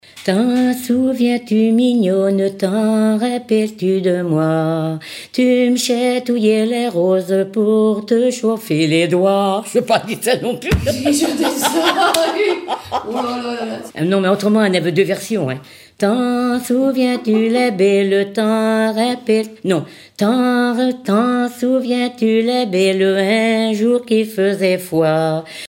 Fonction d'après l'analyste gestuel : à marcher
Pièce musicale éditée